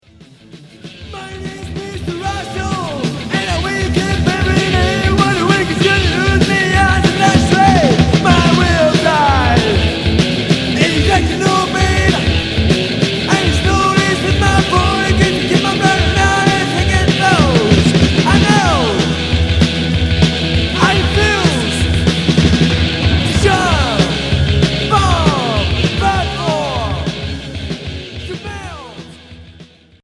Punk Unique 45t